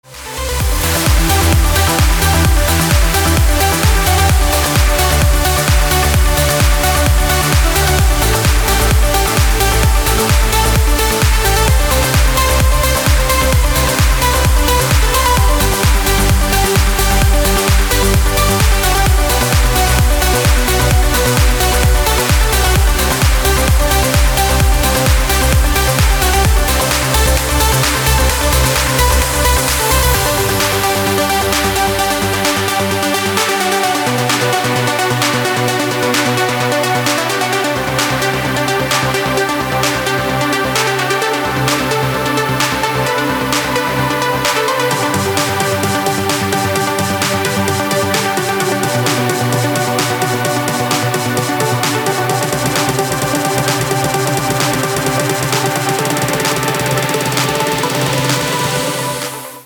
• Качество: 256, Stereo
громкие
dance
Electronic
электронная музыка
без слов
club
энергичные
Trance
бодрые
танцевальные